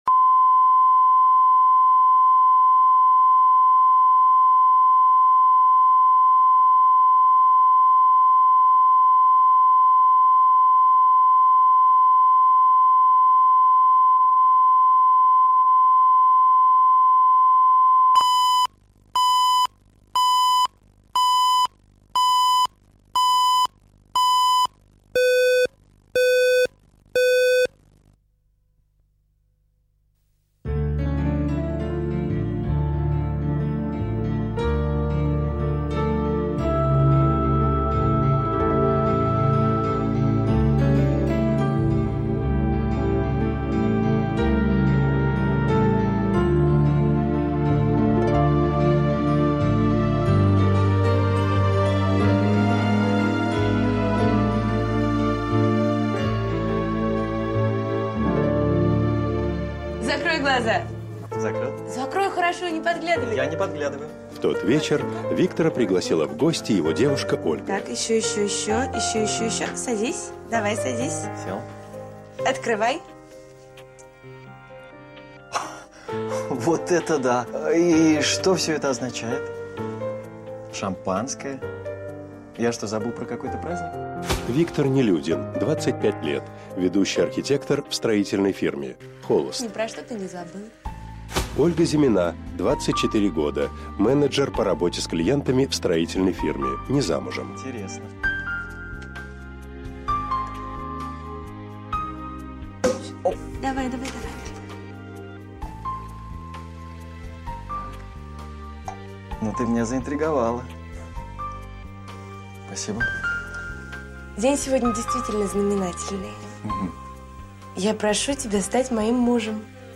Аудиокнига Выбор судьбы | Библиотека аудиокниг